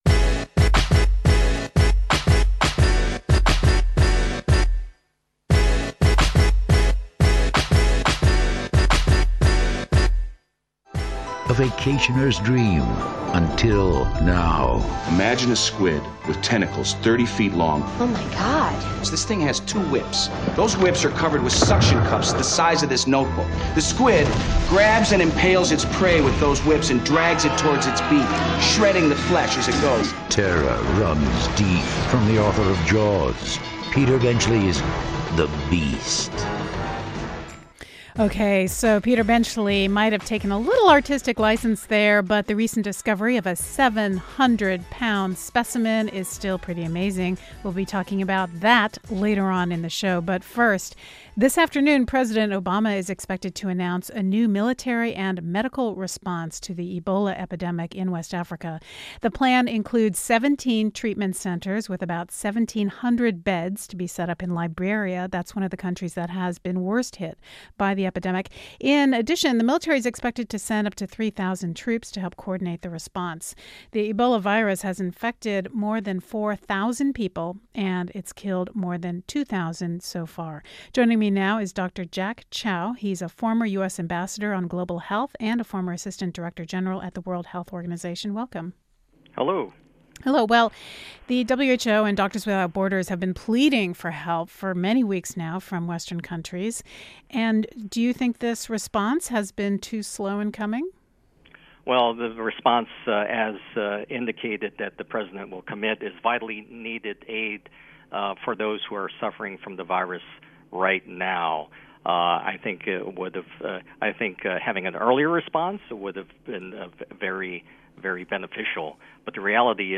President Obama plans to announce a new large-scale response to the Ebola outbreak in West Africa today. We discuss the details and talk to a healthcare worker fighting the epidemic in Liberia.